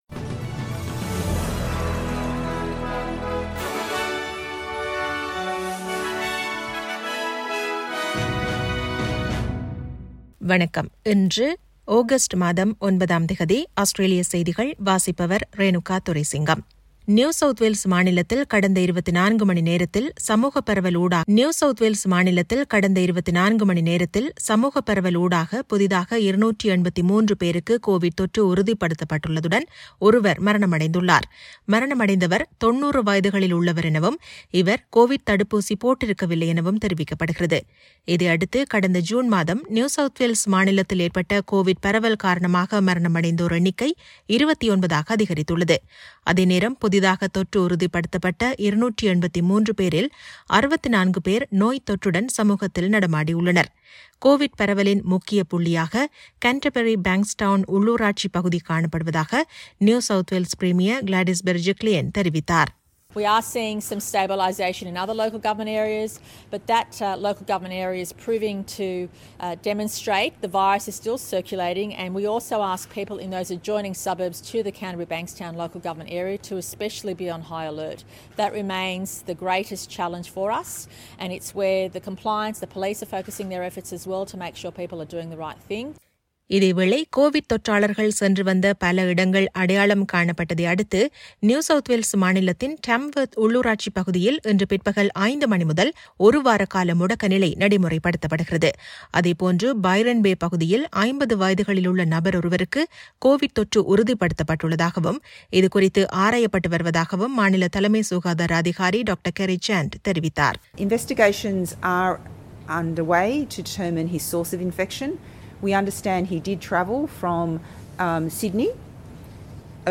SBS தமிழ் ஒலிபரப்பின் இன்றைய (திங்கட்கிழமை 09/08/2021) ஆஸ்திரேலியா குறித்த செய்திகள்.